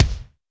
BAL Kick.wav